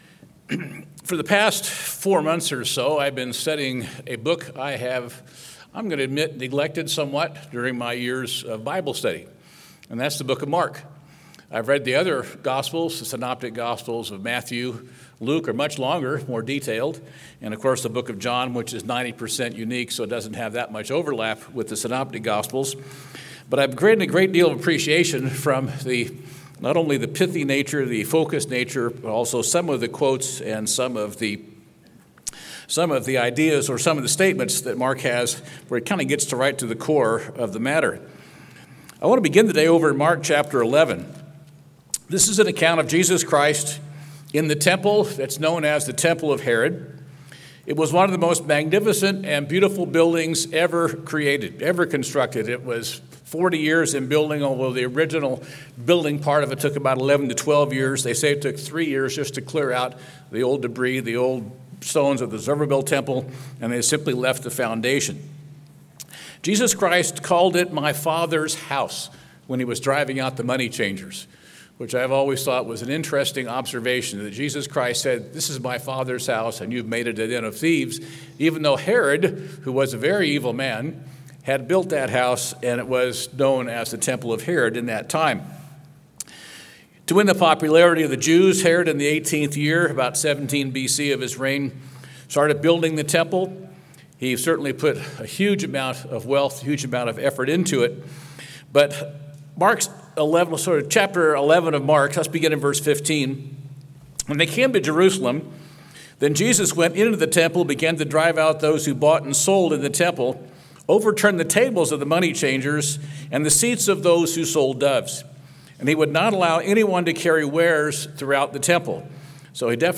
This sermon was given at the Bend-Redmond, Oregon 2022 Feast site.